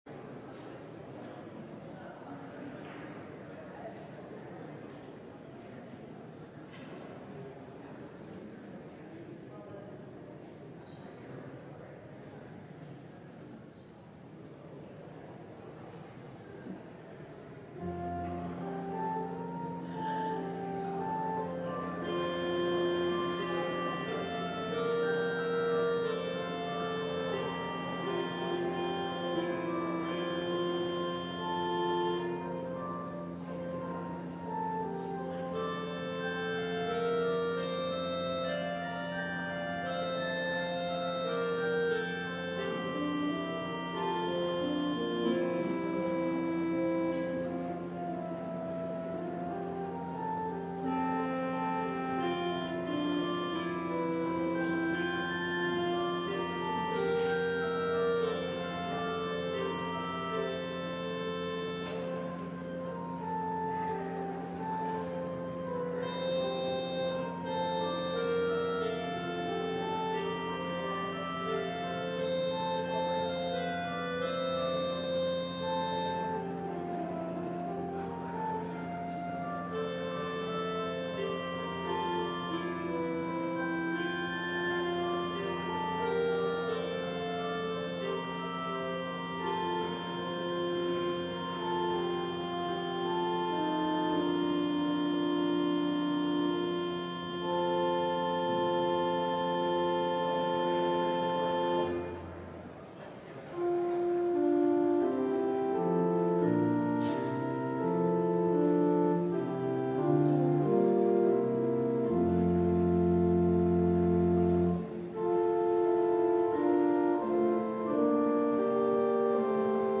LIVE Midday Worship Service - Leadership Ambition
Congregational singing—of both traditional hymns and newer ones—is typically supported by our pipe organ. Vocal choirs, handbell choirs, small ensembles, instrumentalists, and vocal soloists provide additional music offerings.